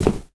step3.wav